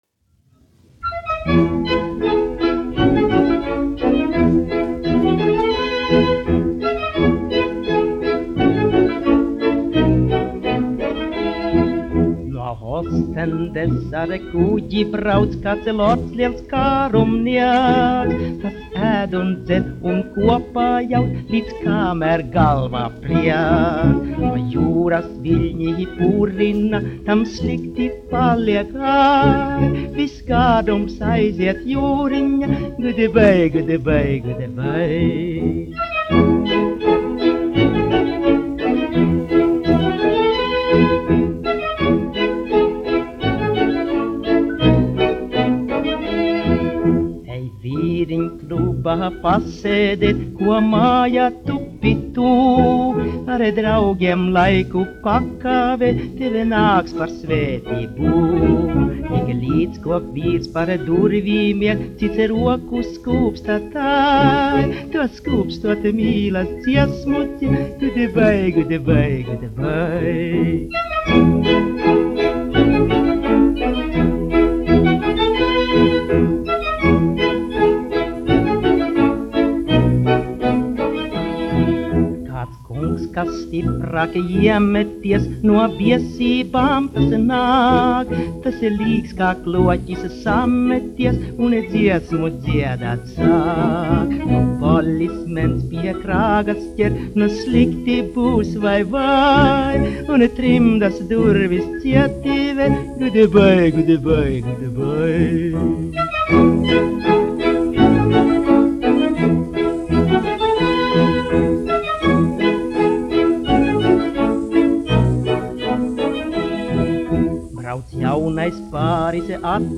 1 skpl. : analogs, 78 apgr/min, mono ; 25 cm
Populārā mūzika